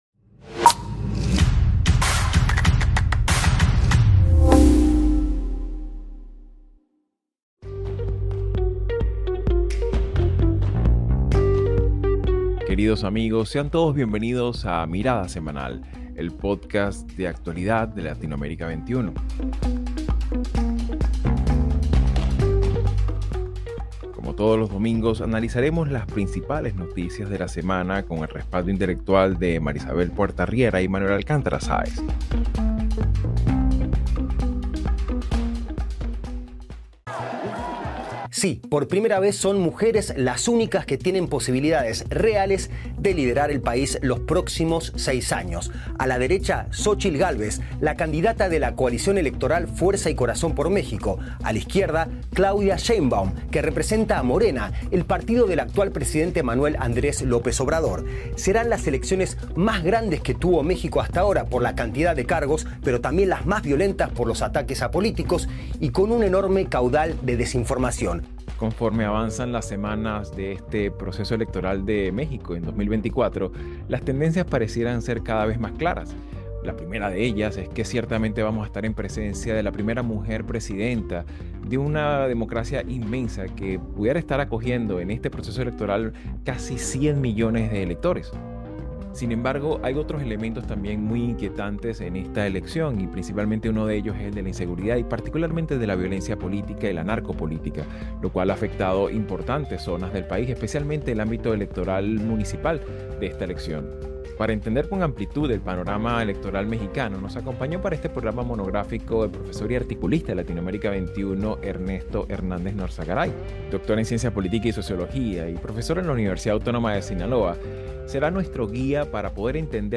Mirada Semanal | Panorama electoral mexicano 2024. Programa monográfico. Entrevista